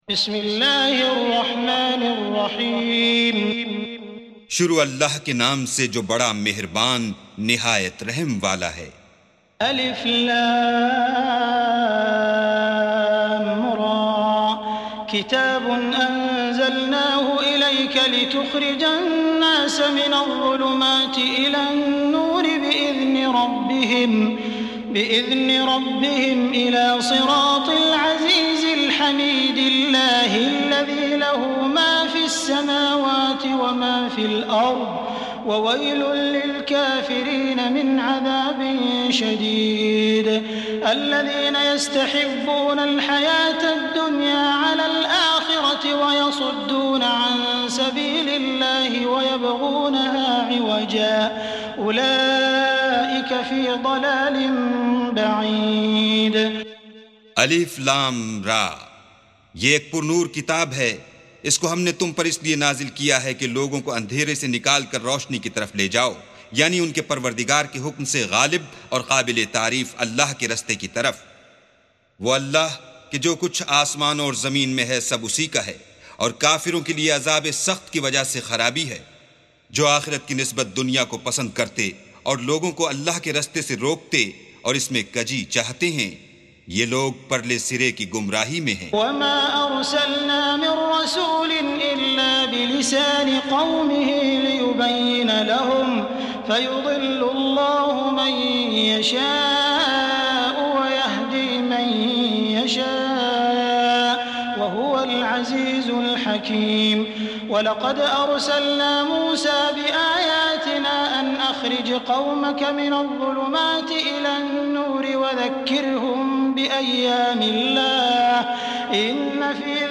سُورَةُ ابراهيم بصوت الشيخ السديس والشريم مترجم إلى الاردو